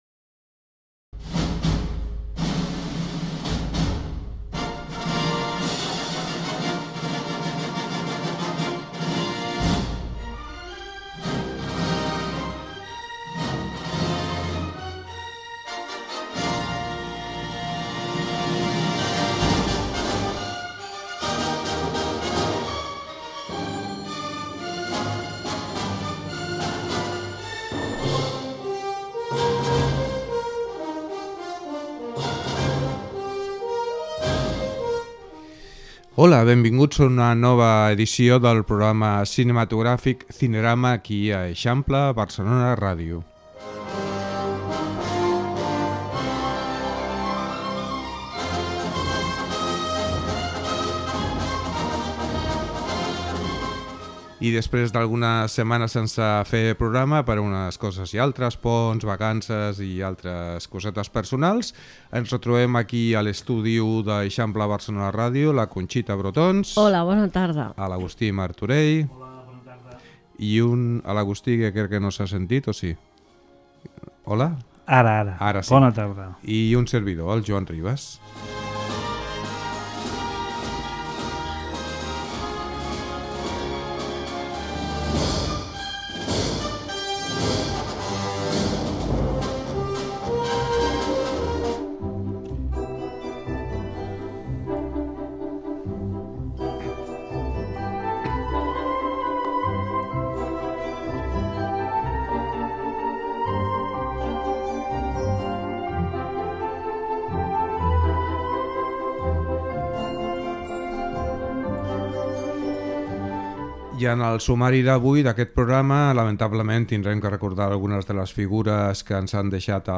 Sintonia, presentació, equip, sumari, mort de l'actriu Concha Velasco amb un record al seu discurs quan va rebre el premi Goya d'Honor